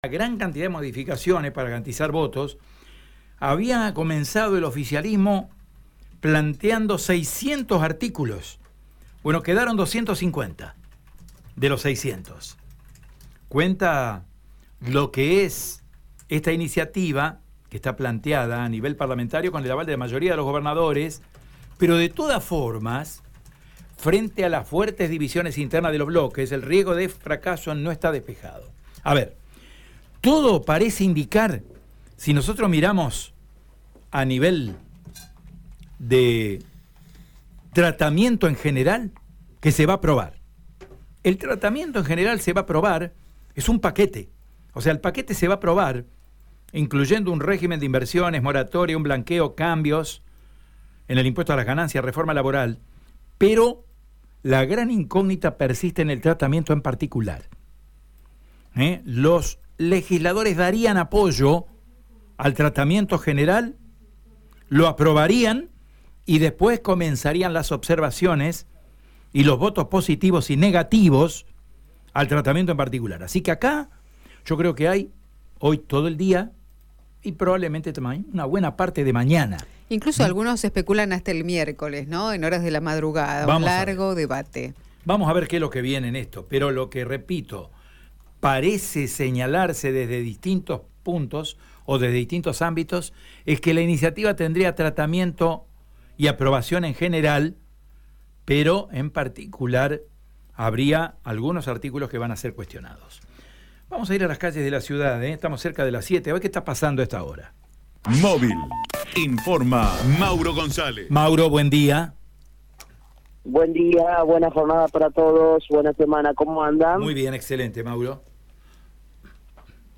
«21 años de lucha contra la impunidad», afirma una de las pancartas colgadas alrededor del espacio frente a Casa de Gobierno, según indicó el móvil de Radio EME.